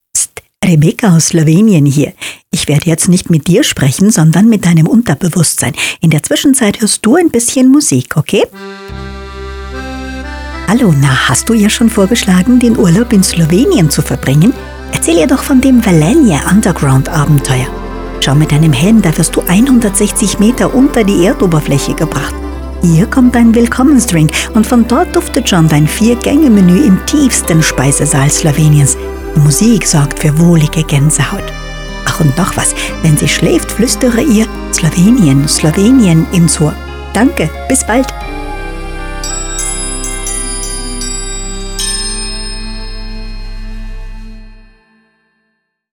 Promocija 5* doživetja Velenje Underground bo potekala vse do konca meseca novembra na radijskih postajah: Klassik Fm in BEats Fm na Bavarskem.